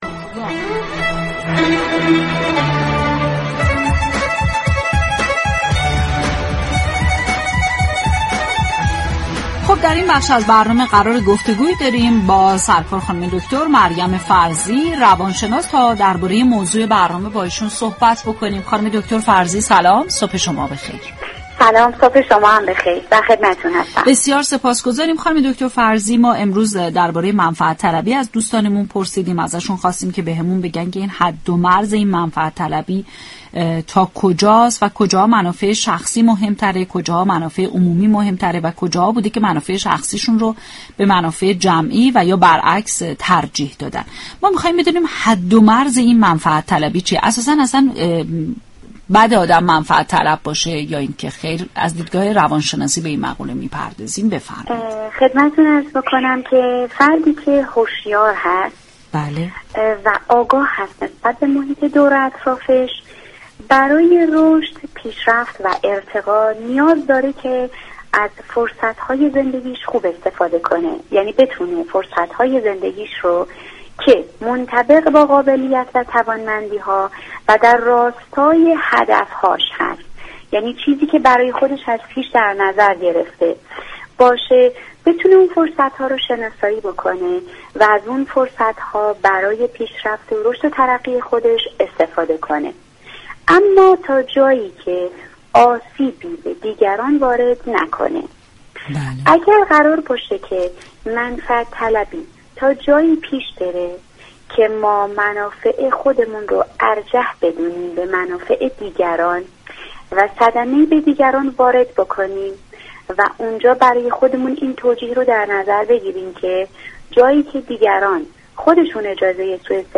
فایل صوتی مربوط به این گفتگو را در زیر بشنوید .